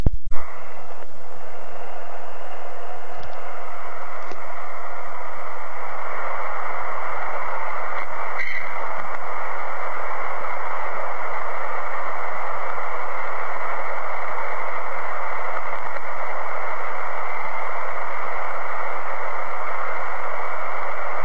Na výstup zařadil attenuátor a tak výstupní výkon nastavil na 2mW.
Při poslechu nahrávky je však jasně slyšet, že to tam je.